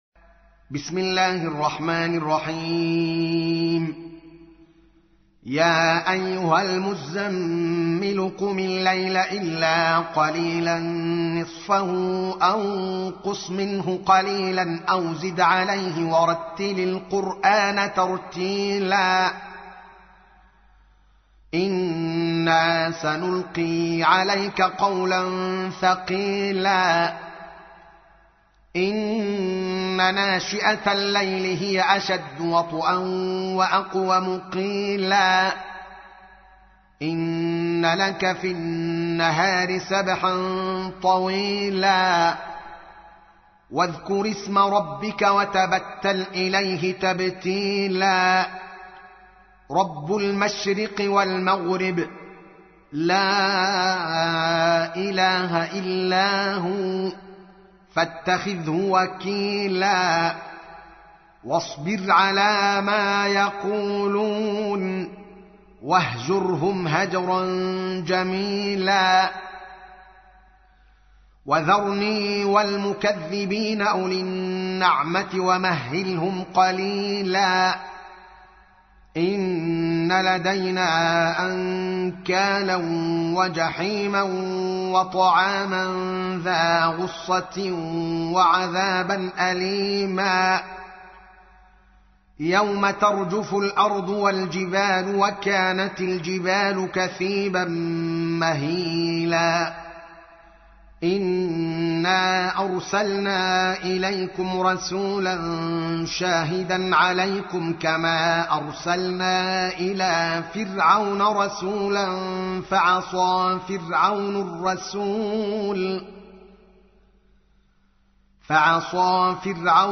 تحميل : 73. سورة المزمل / القارئ الدوكالي محمد العالم / القرآن الكريم / موقع يا حسين